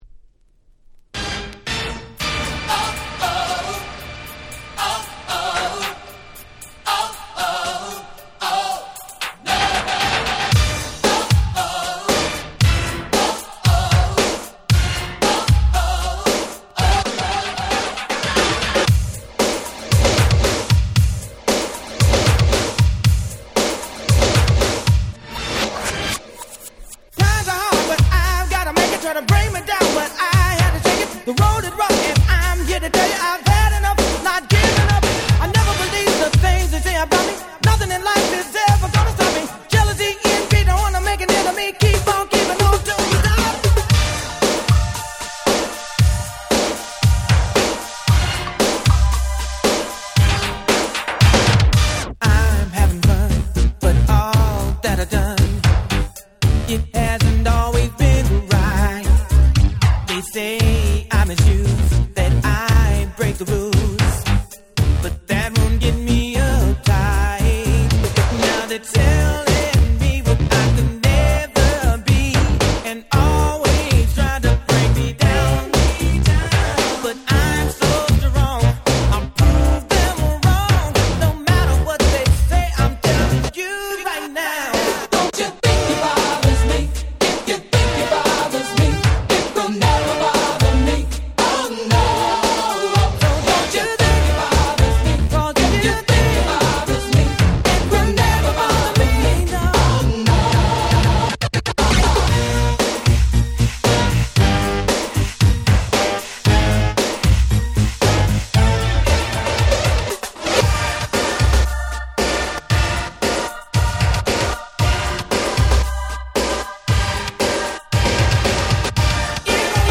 89' Smash Hit R&B / New Jack Swing !!
バッキバキのNew Jack Swing Beatが弾ける名曲！！